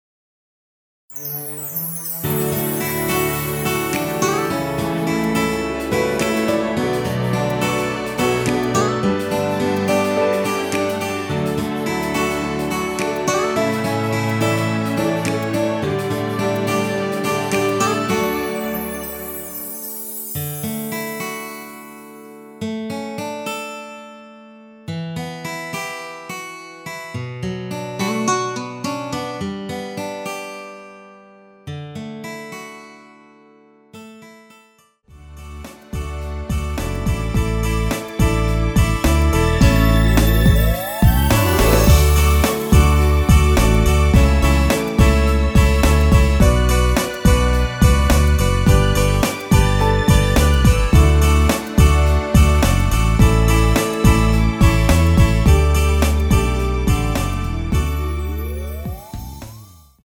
페이드 아웃곡이라 라이브 사용하시기 좋게 엔딩을 만들어 놓았습니다.
원키에서(-4)내린 MR입니다.
앞부분30초, 뒷부분30초씩 편집해서 올려 드리고 있습니다.